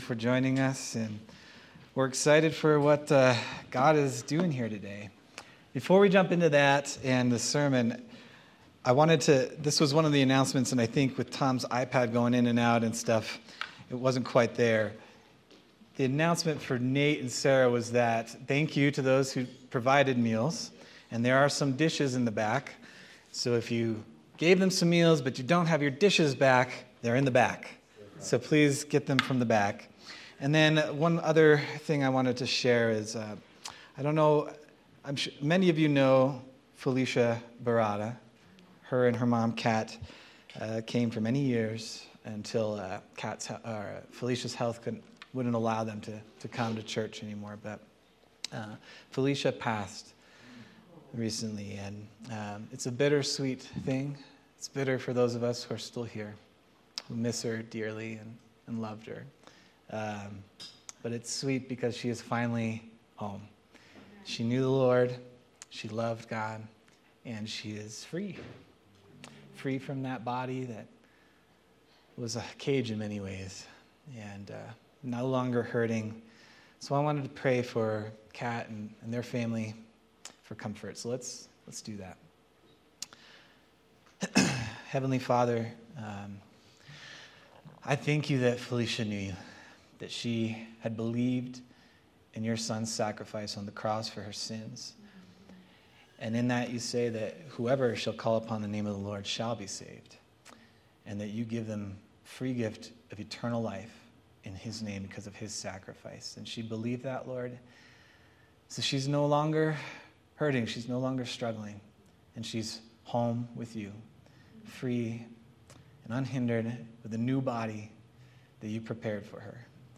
April 27th, 2025 Sermon